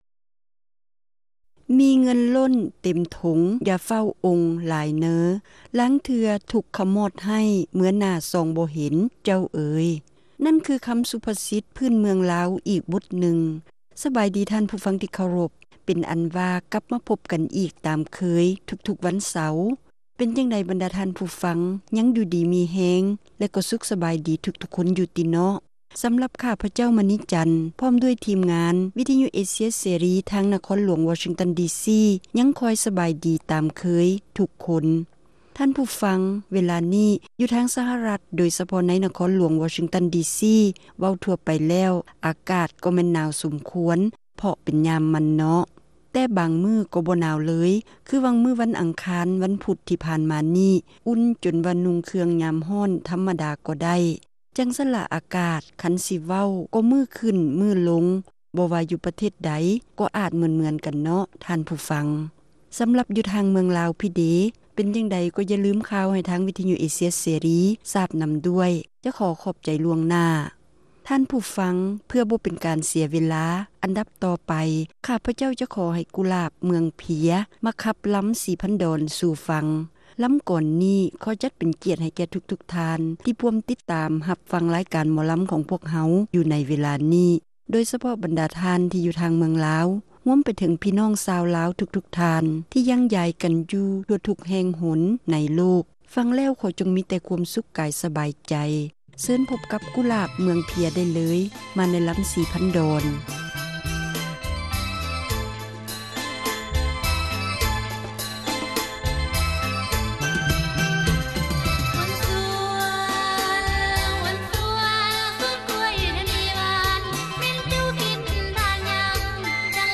ຣາຍການໜໍລຳ ປະຈຳສັປະດາ ວັນທີ 11 ເດືອນ ມົກະຣາ ປີ 2008